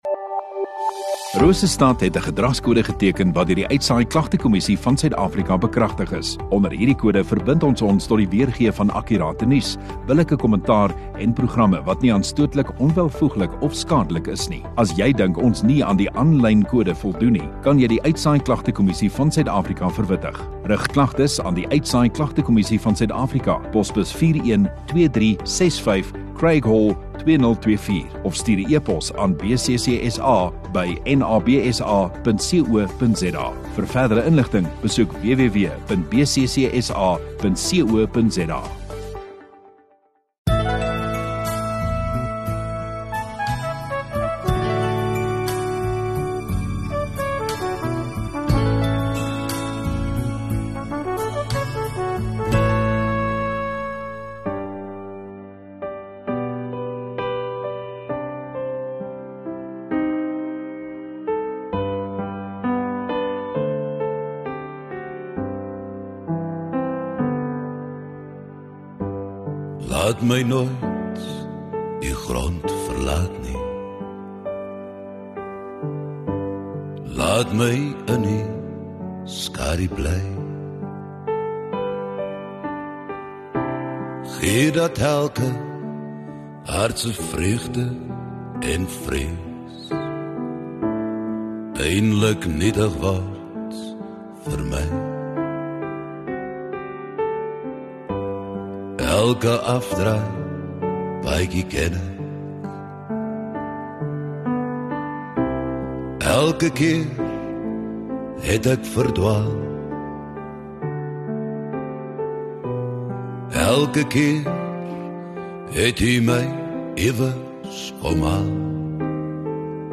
29 Sep Sondagaand Erediens